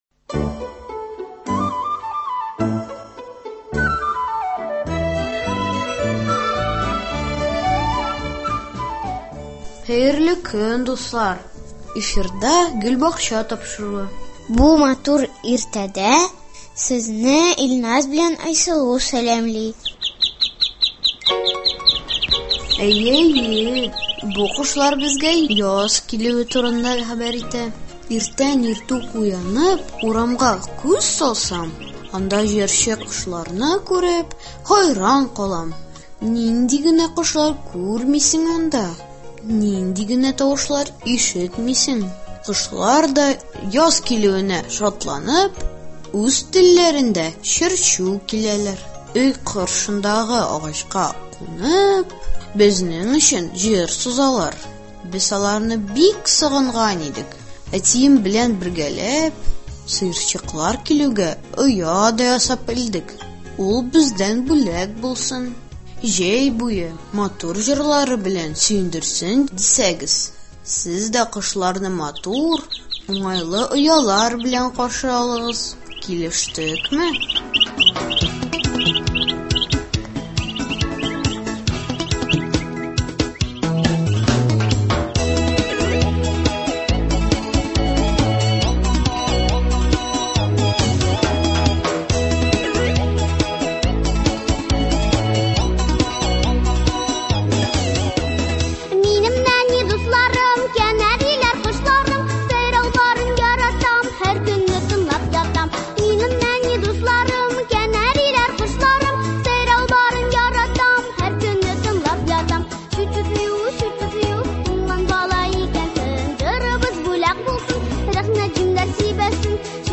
Кошлар җырларын тыңлап, табигать уянуын күзәтербез. Шулай ук бакчага йөрүче дусларыбыз чыгышлары да сезнең күңелгә хуш килер.